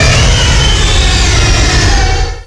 direct_sound_samples / cries / primal_kyogre.aif
primal_kyogre.aif